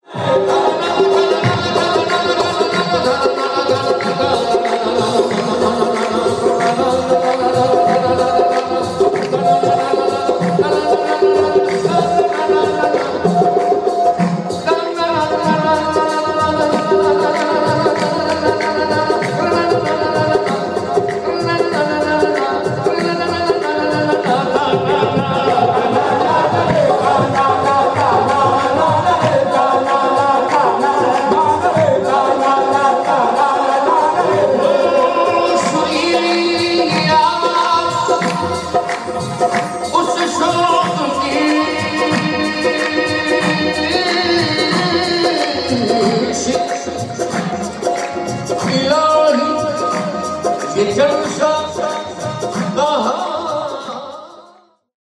Qawwali night in Karachi